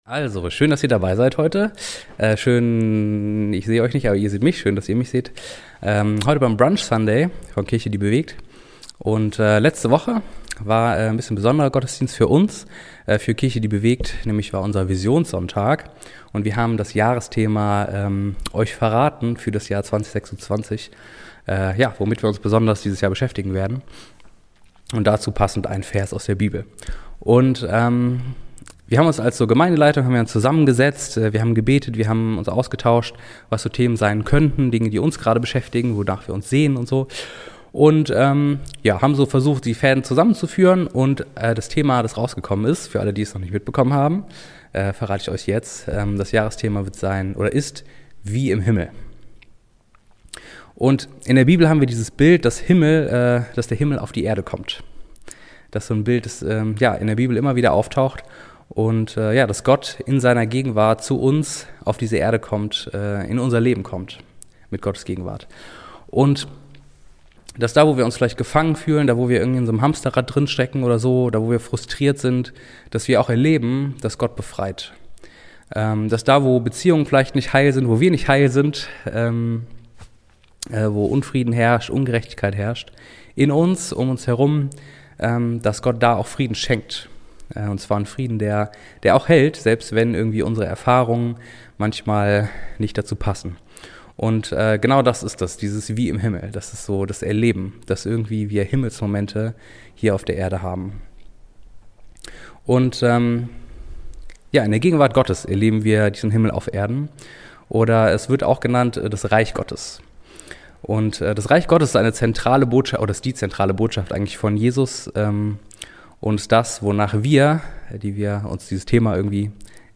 Gott schafft den Himmel auf die Erde. Darum ging es an unserem Visionssonntag. Was bedeutet das für Kirche?